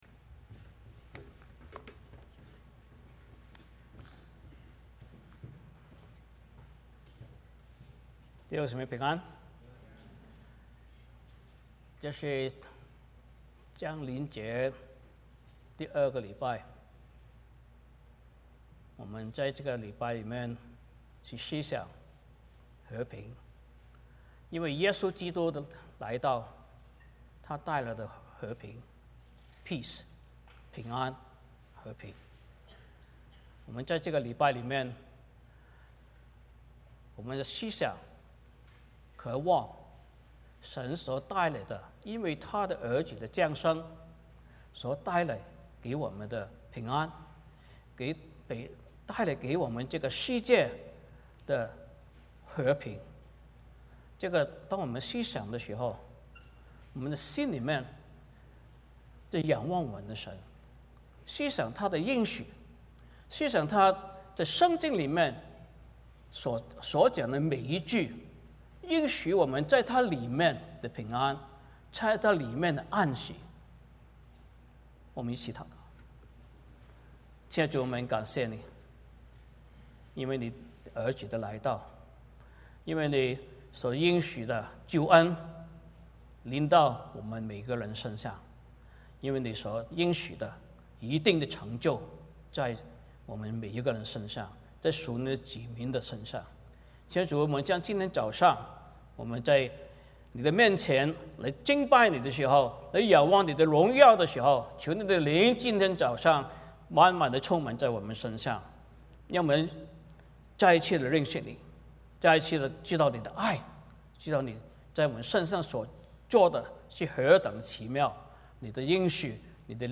欢迎大家加入我们国语主日崇拜。
1-7 Service Type: 圣餐主日崇拜 欢迎大家加入我们国语主日崇拜。